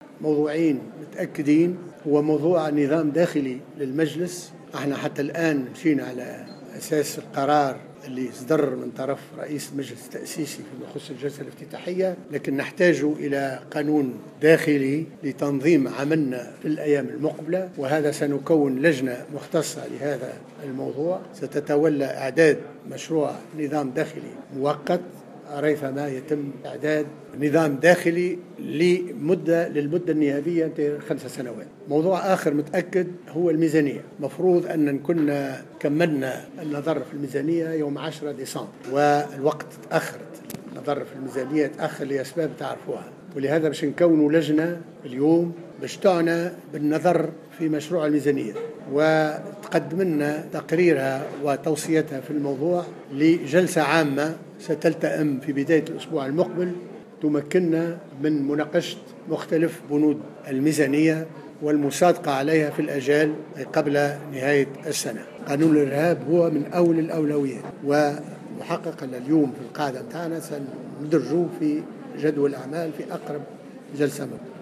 أعلن رئيس مجلس نواب الشعب محمد الناصر، في أول ندوة صحفية عقدها اليوم الخميس، عق توليه مهامه، أن أولويات المجلس بعد المصادقة على ميزانية الدولة لسنة 2015 ، ستكون توفير أسباب الأمن ورفع التحديات الإقتصادية والإجتماعية التي تمر بها البلاد، ومعالجة مشاكل الشباب، مبرزا ضرورة المصادقة على ميزانية الدولة قبل موفي 31 ديسمبر الجاري.